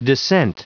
Prononciation du mot dissent en anglais (fichier audio)
Prononciation du mot : dissent